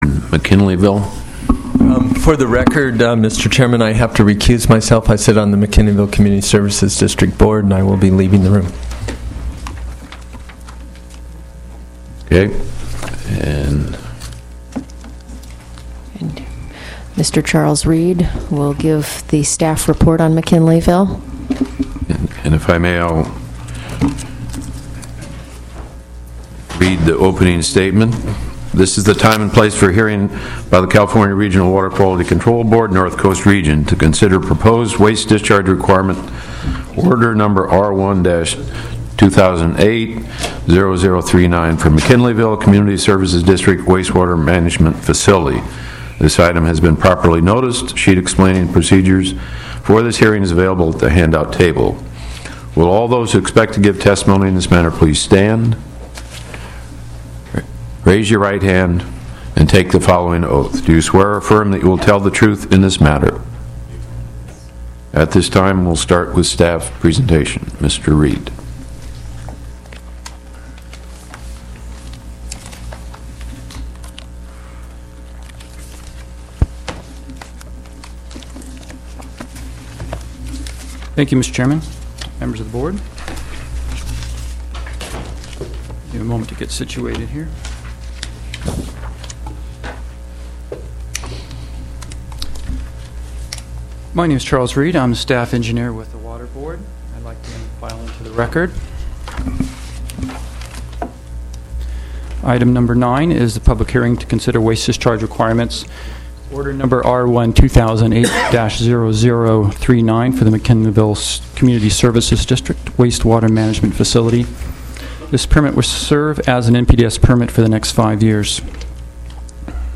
Meeting Announcement - Agenda
8:30 a.m., June 12, 2008 Regional Water Board Office 5550 Skylane Blvd., Suite A Santa Rosa, CA 95403